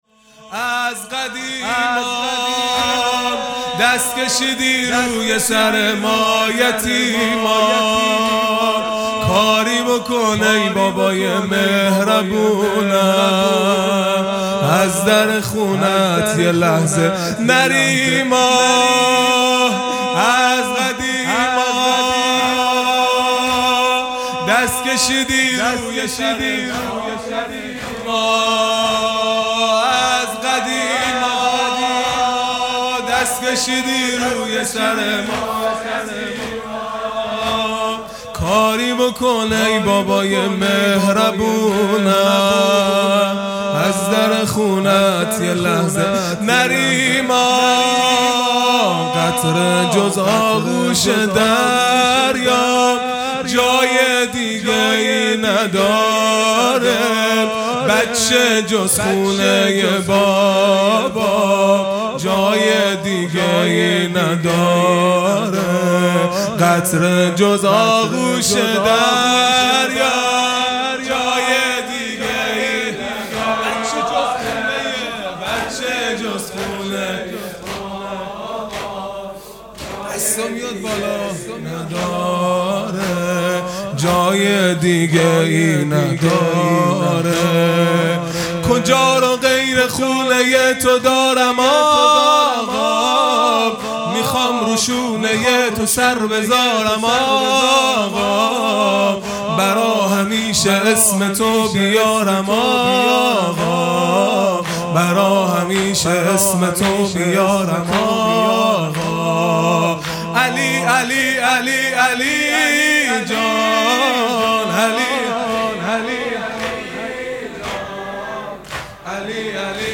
جلسۀ هفتگی | مناجات ماه رمضان | پنجشنبه 1 اردیبهشت 1401